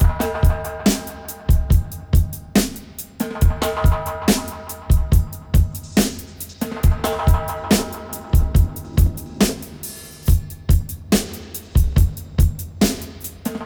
141-FX-02.wav